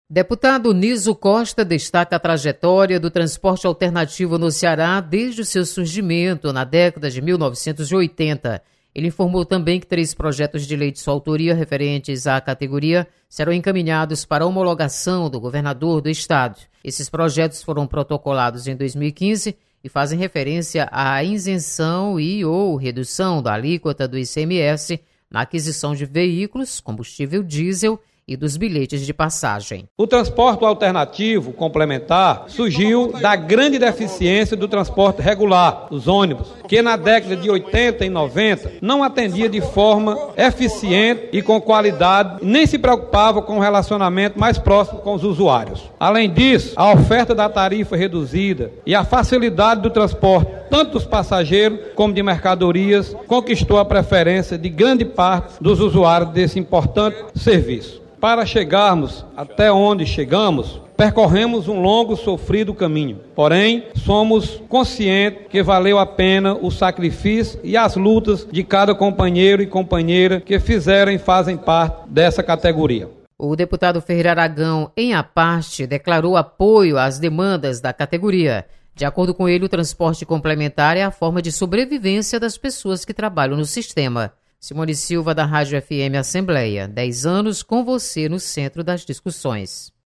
Deputado destaca trajetória Transporte Alternativo do Ceará. Repórter